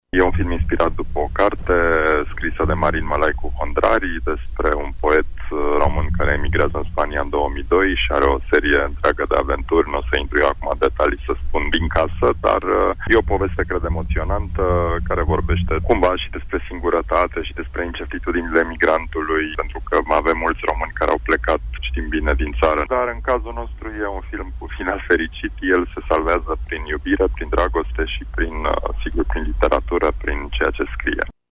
Pentru Radio Tg.Mureș, regizorul Tudor Giurgiu: